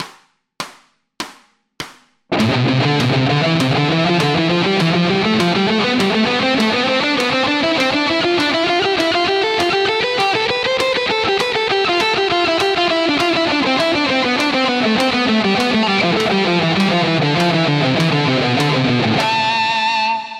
Секвенция – это мелодическая фраза, повторяющаяся в неизменном виде, но начинающаяся с разных ступеней гаммы.
Аудио (100 УВМ)